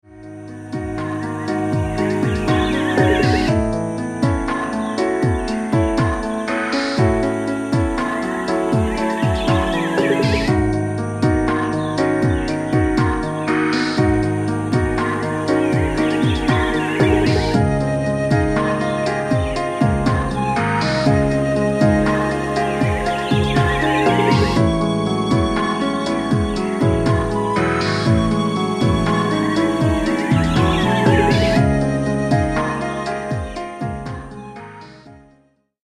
インストを中心にコーラスも織り交ぜた計5曲、20分程度のオリジナル曲を収録。
「聞き込む音楽」というよりは、さりげなく部屋で聞く音楽を目指しました。
ジャンル： EasyListening, NewAge